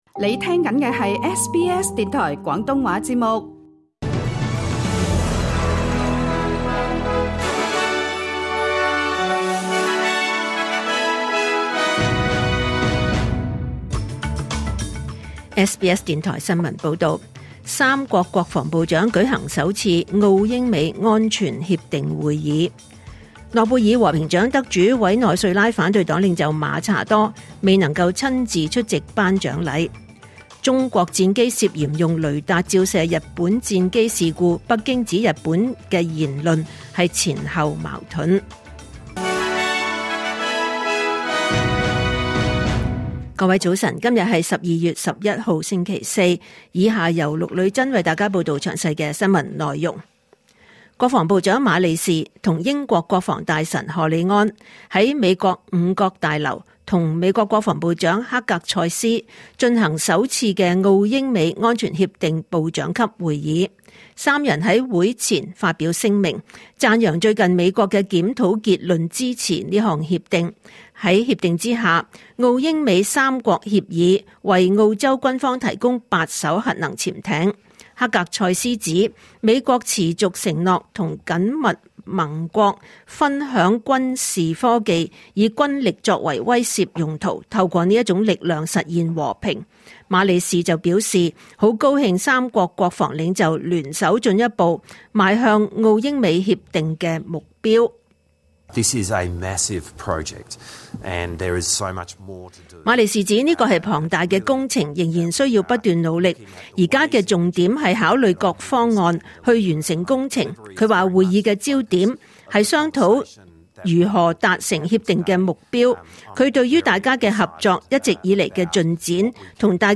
2025年12月11日，SBS廣東話節目九點半新聞報道。